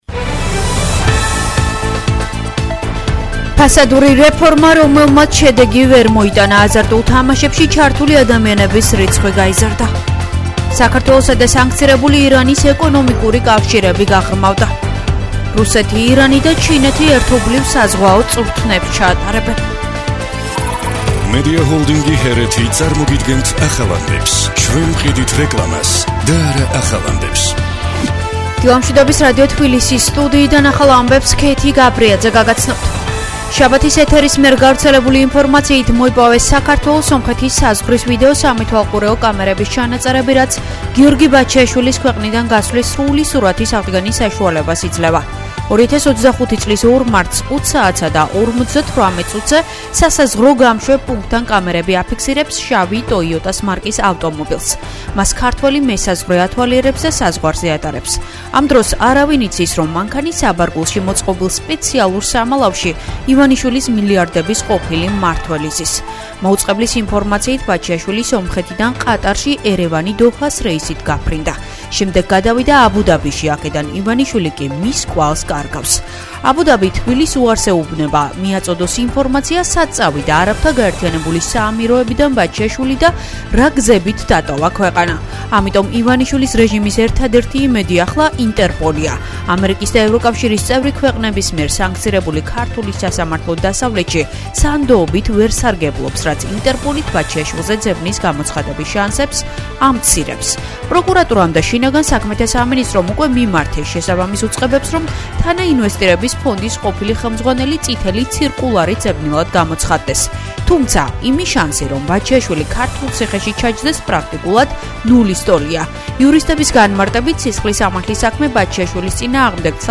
ახალი ამბები 10:00 საათზე